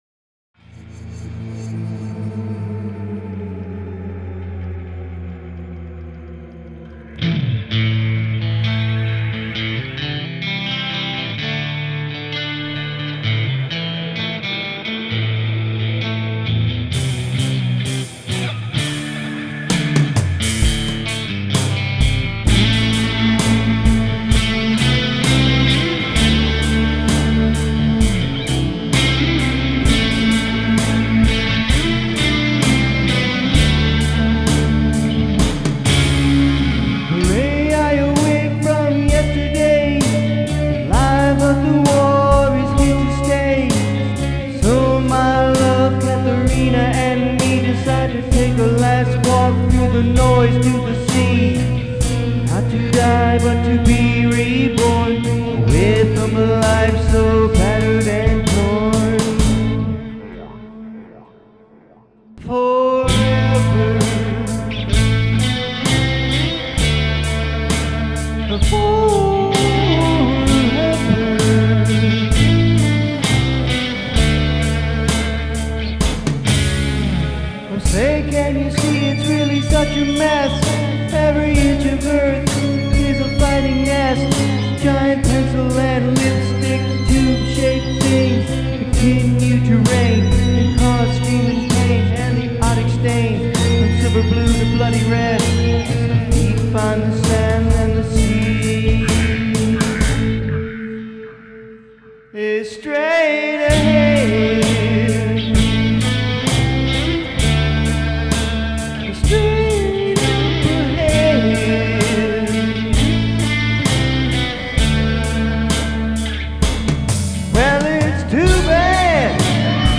with the Palmer PGA-04